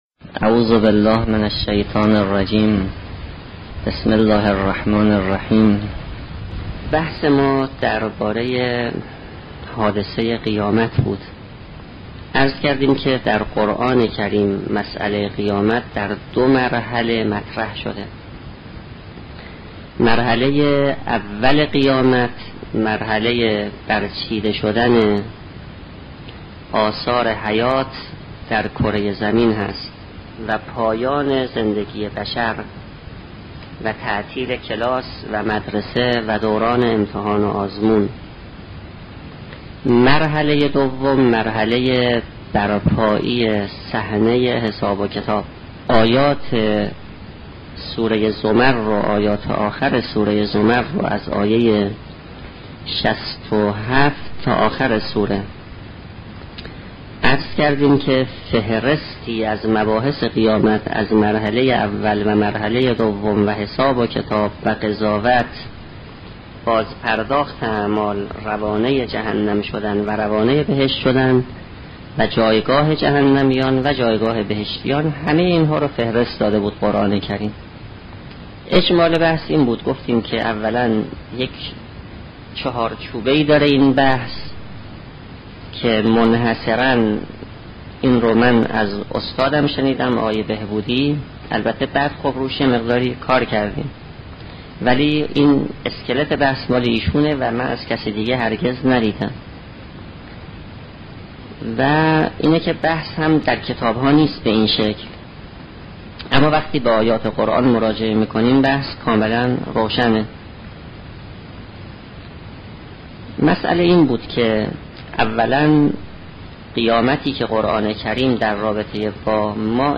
سخنرانی استاد سید محسن میرباقری با موضوع معاد
14 بخش سخنرانی معاد با استاد میرباقری